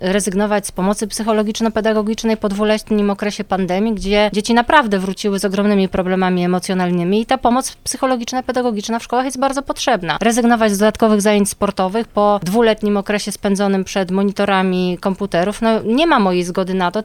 Mimo, że proponowana przez dostawcę energii elektrycznej cena drastycznie wzrosła i trzeba będzie wprowadzić duże ograniczenia w korzystaniu z niej, to na pewno nie kosztem dzieci, mówi wiceprezydent Katarzyna Kalinowska: